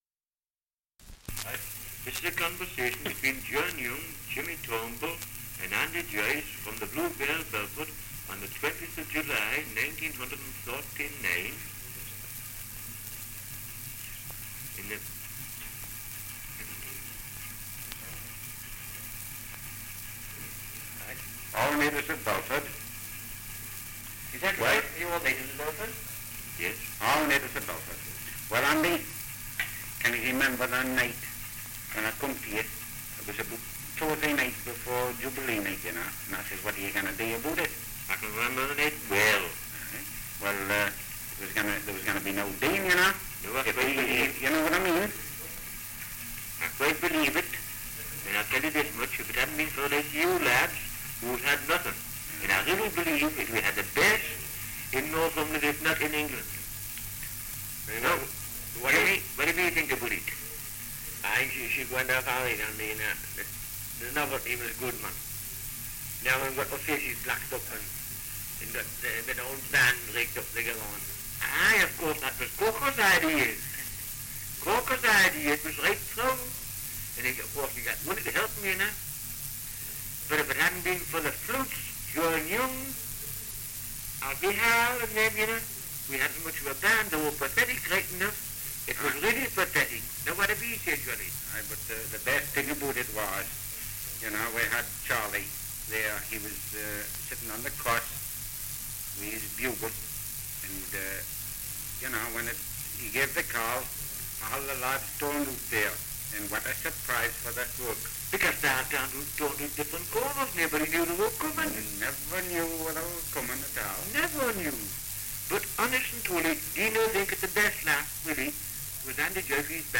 2 - Dialect recording in Belford, Northumberland
78 r.p.m., cellulose nitrate on aluminium